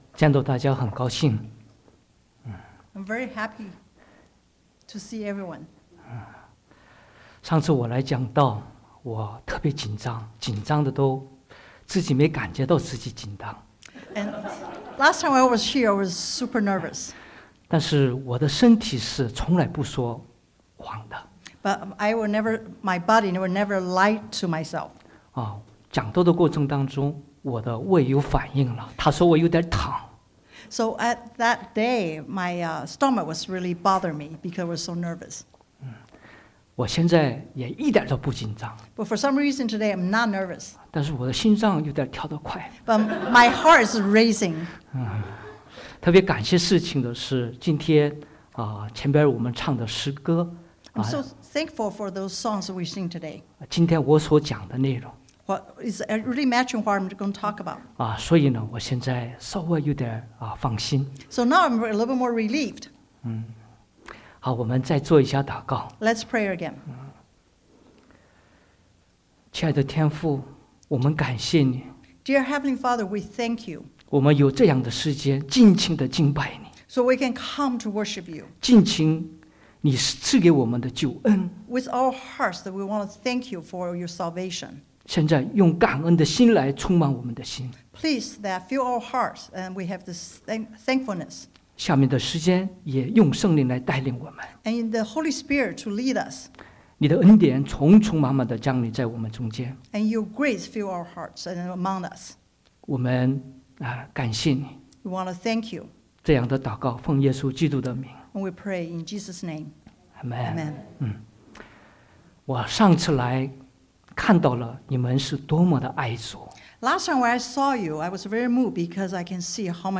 Romans 8:14-17 Service Type: Sunday AM Bible Text